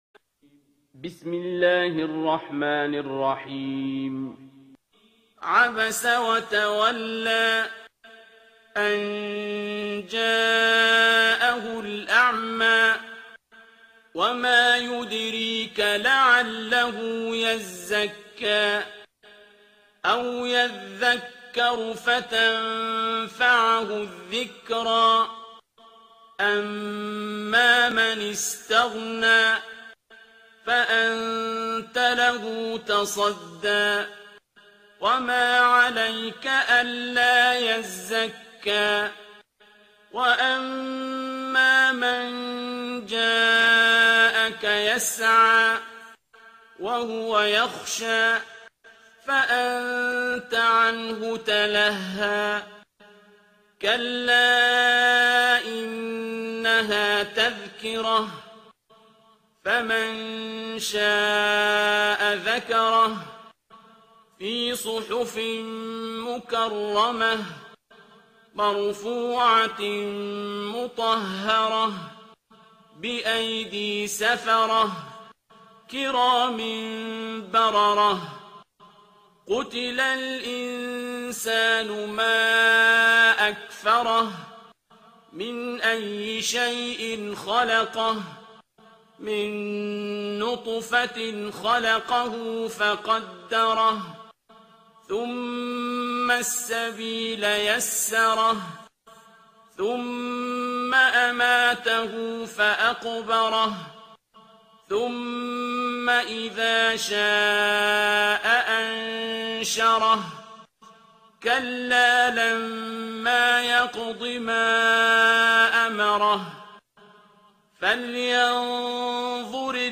ترتیل سوره عبس با صدای عبدالباسط عبدالصمد